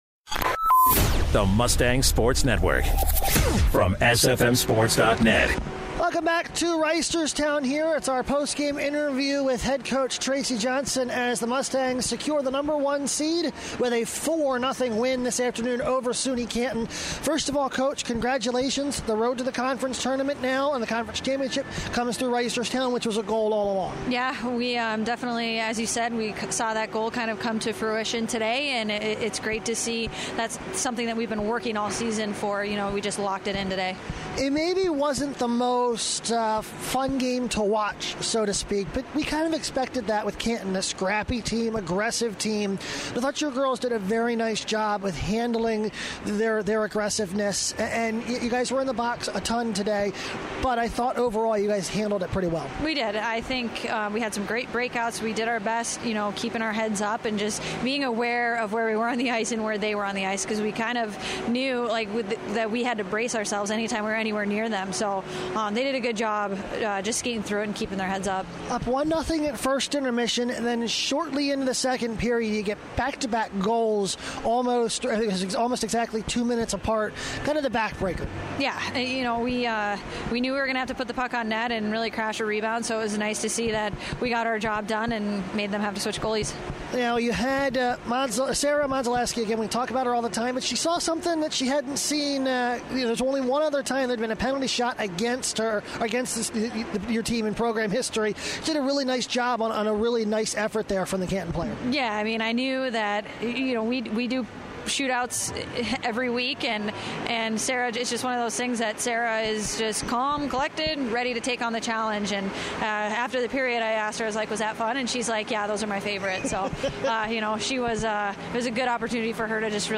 2/19/16: Stevenson Women's Ice Hockey Post Game Show